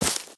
sounds / material / human / step / t_bush2.ogg